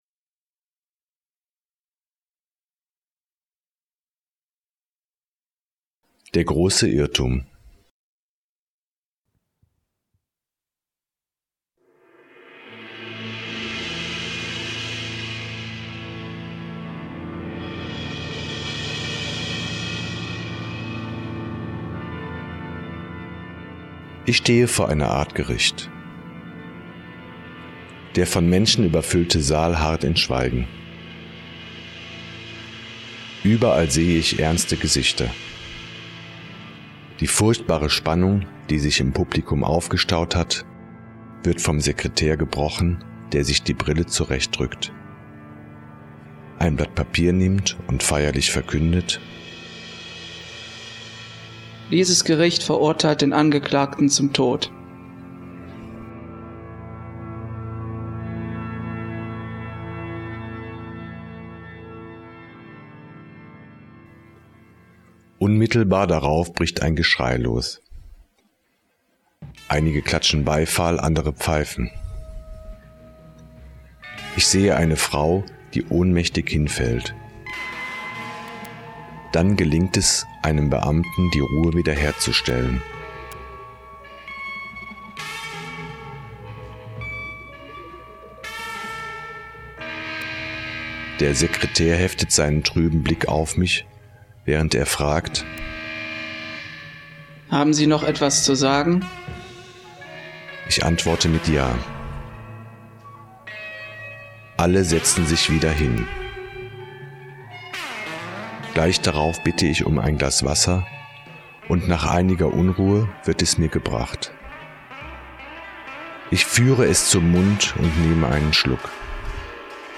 Geleitete Erfahrungen - Der Grosse Irrtum - Gemeinschaften von Silos Botschaft